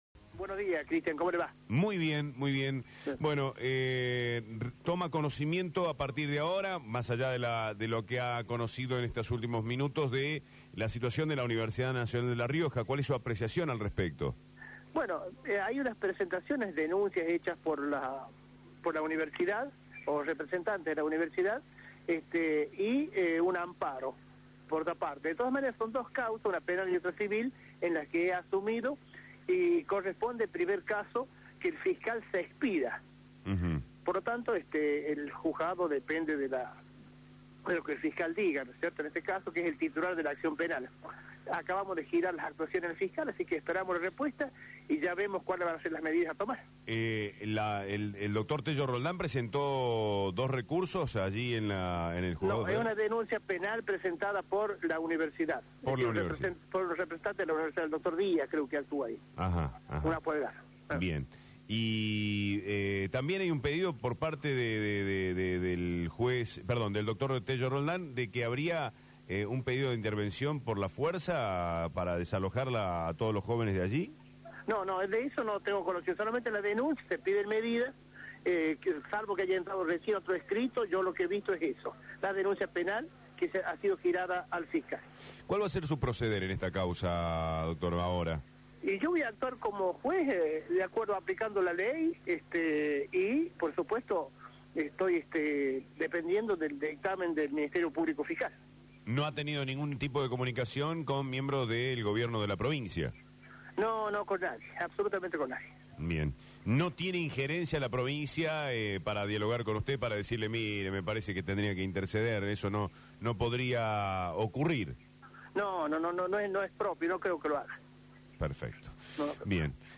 Las declaraciones del abogado Azcurra por Radio La Red